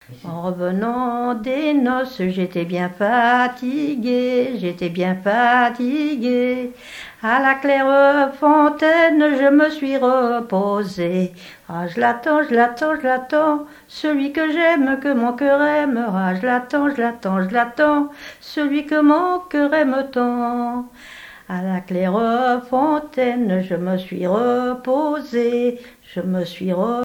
Genre laisse
témoignage sur les noces et chansons traditionnelles
Pièce musicale inédite